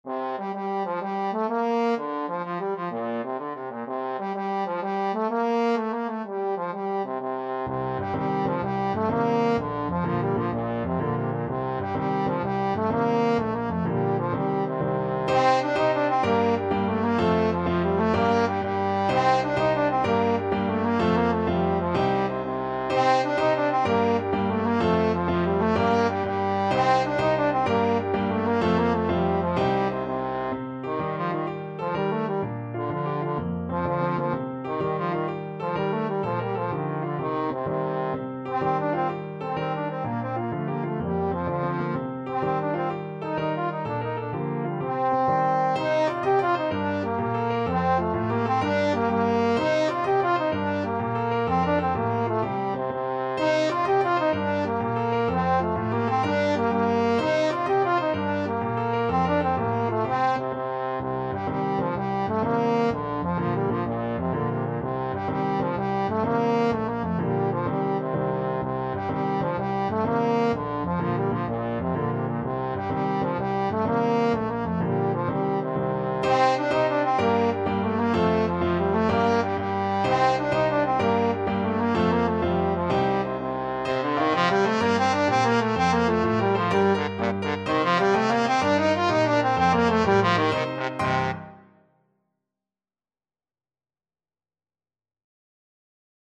Trombone
G minor (Sounding Pitch) (View more G minor Music for Trombone )
12/8 (View more 12/8 Music)
Fast .=c.126
Irish